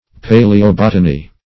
Paleobotany \Pa`le*o*bot"a*ny\, n. [Paleo- + botany.]